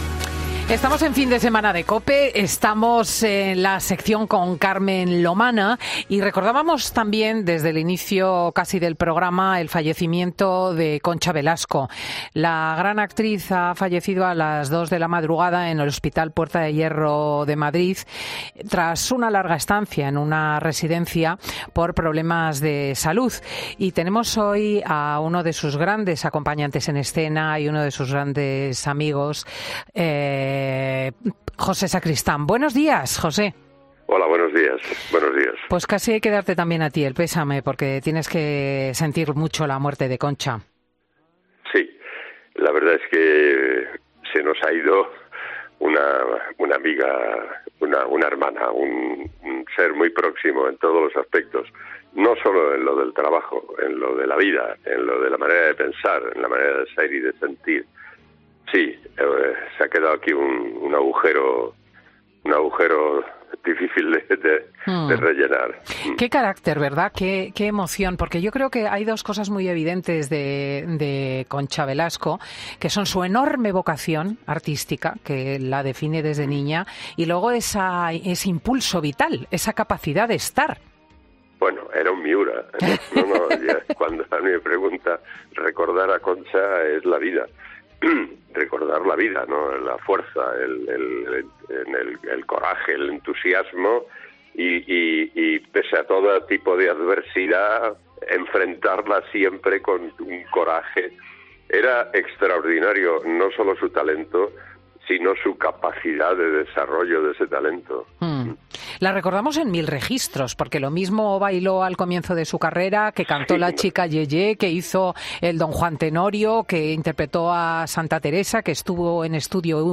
El actor ha recordado en Fin de Semana COPE a una de sus grandes acompañantes en escena y una de sus grandes amigas.